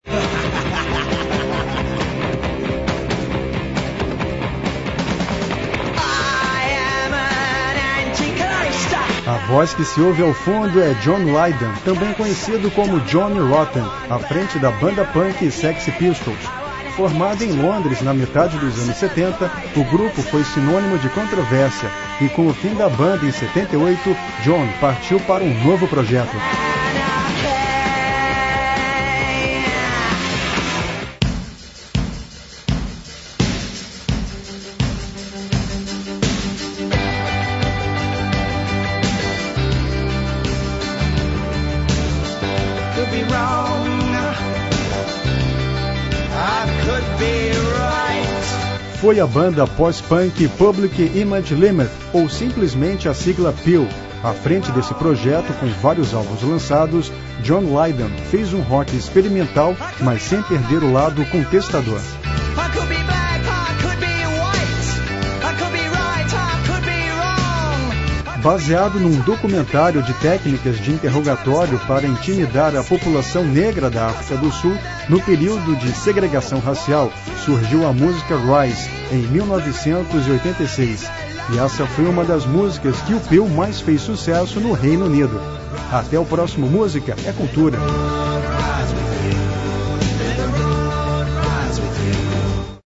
A voz que se ouve ao fundo é John Lydon   também conhecido como Johnny Rotten    à frente da banda punk Sex Pistols.